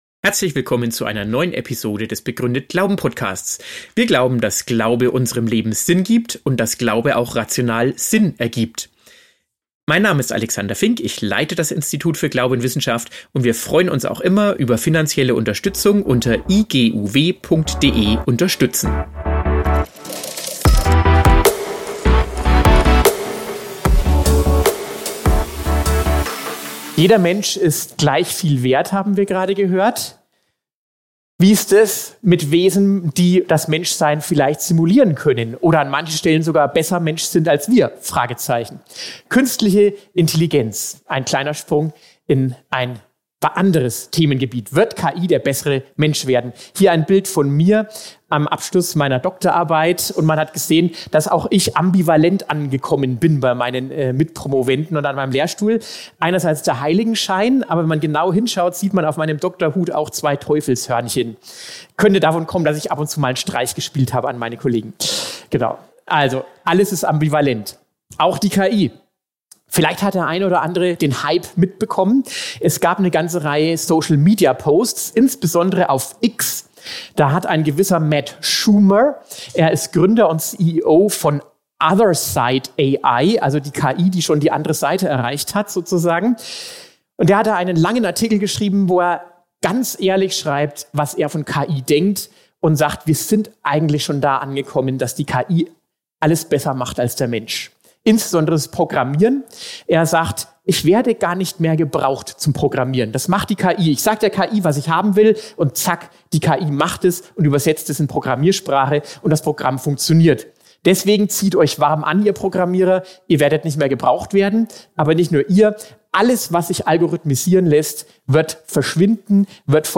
Dieser Vortrag ist auf unserer diesjährigen Begründet-Glauben-Konferenz in Heimsheim entstanden!